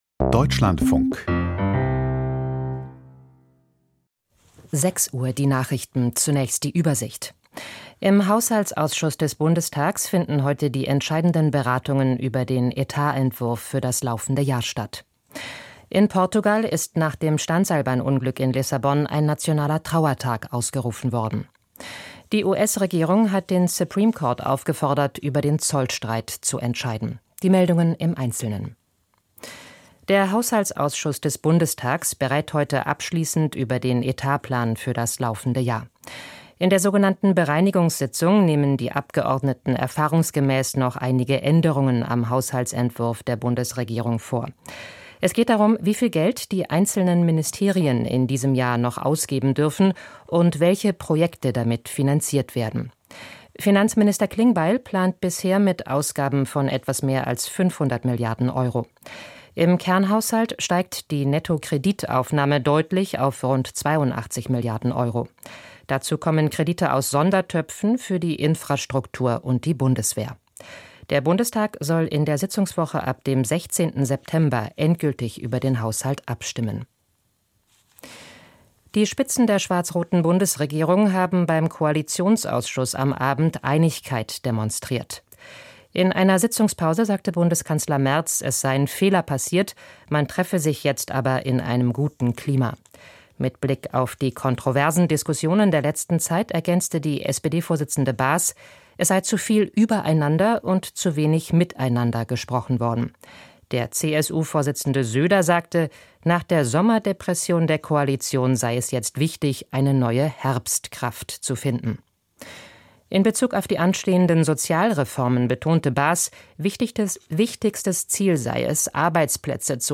Die Nachrichten vom 04.09.2025, 06:00 Uhr